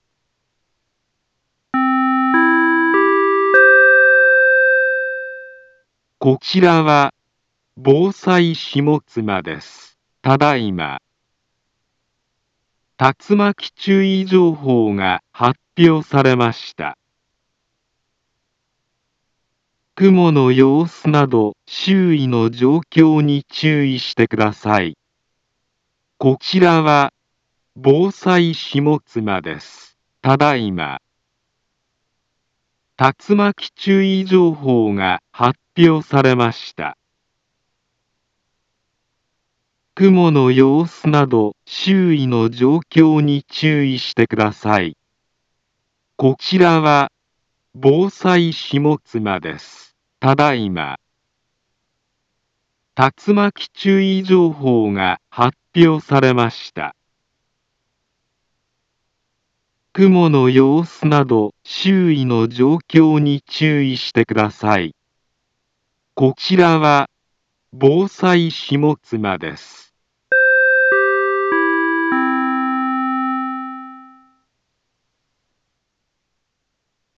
Back Home Ｊアラート情報 音声放送 再生 災害情報 カテゴリ：J-ALERT 登録日時：2022-08-13 20:05:10 インフォメーション：茨城県南部は、竜巻などの激しい突風が発生しやすい気象状況になっています。